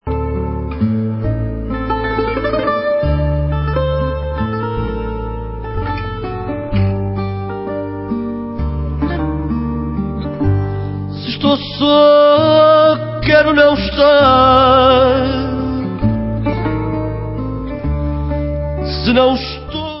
sledovat novinky v oddělení World/Fado